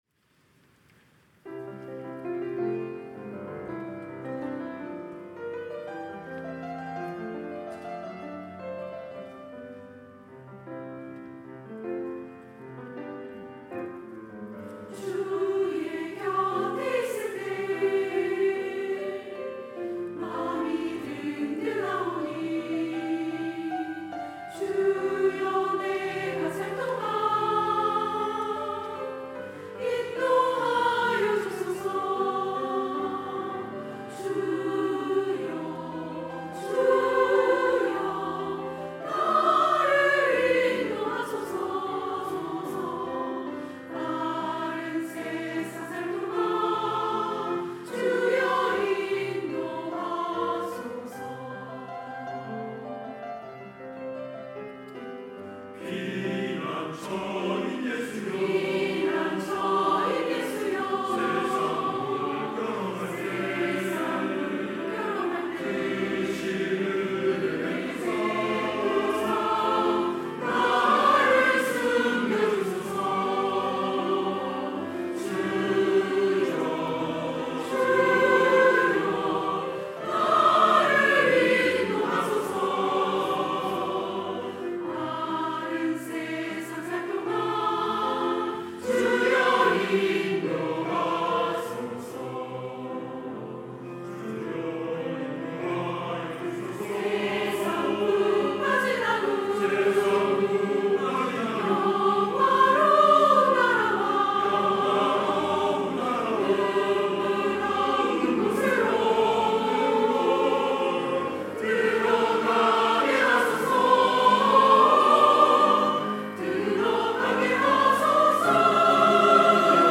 시온(주일1부) - 주의 곁에 있을 때
찬양대